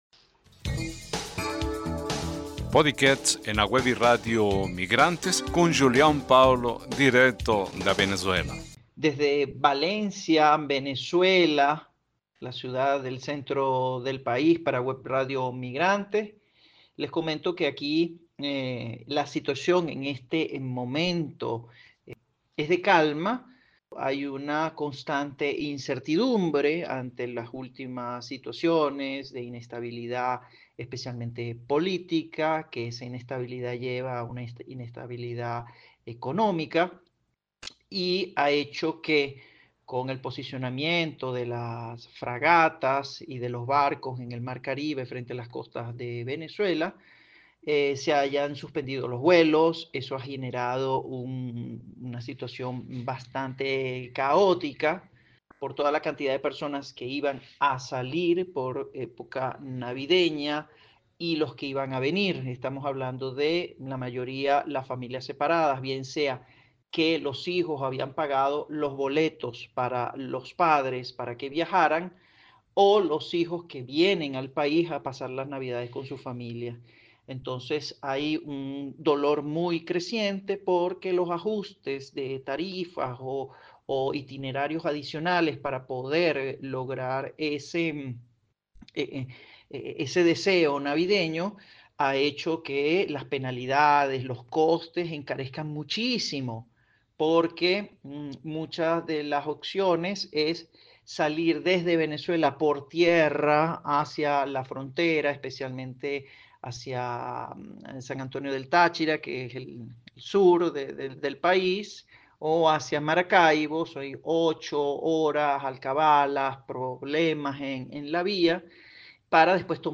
Gravado em Venezuela